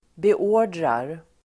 Uttal: [be'å:r_drar]